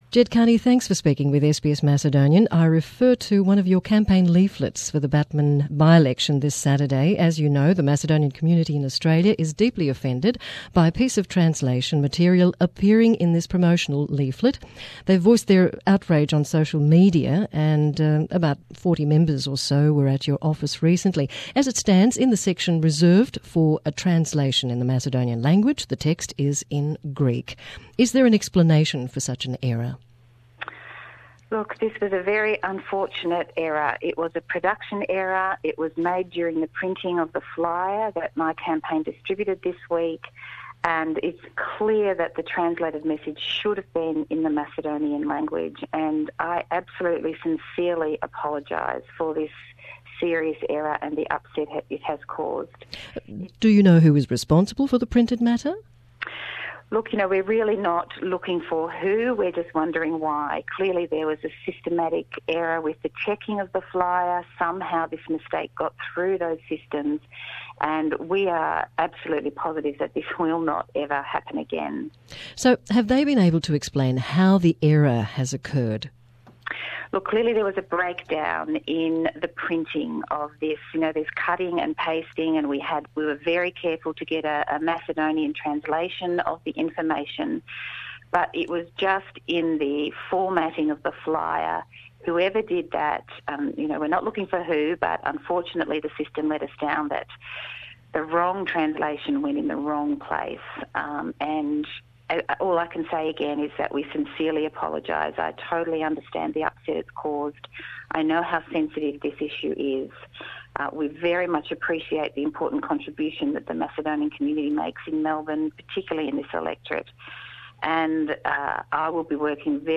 In an interview for SBS Macedonian, Labor candidate for the seat of Batman, Ged Kearney says the appearance of a translation in Greek under a headline 'Macedonian' in one of her campaign leaflets, was a production error.